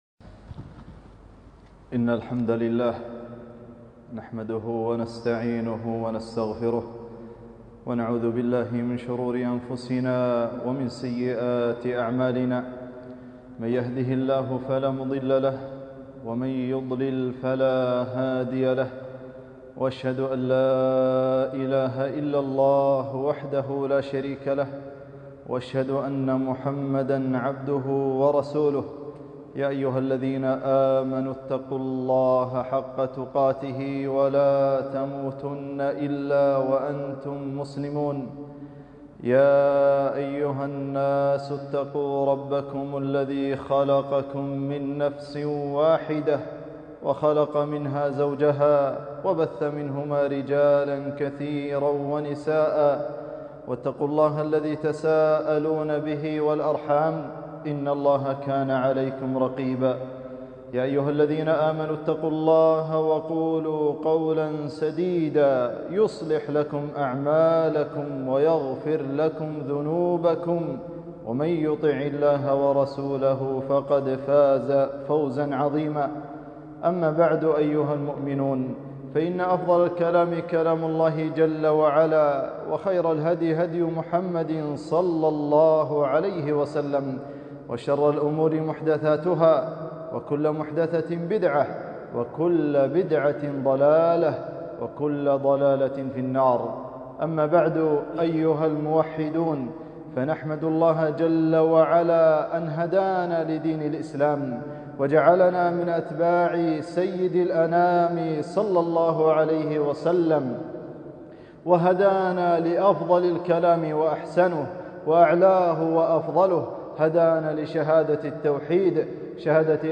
خطبة - فضائل كلمة لا اله الا الله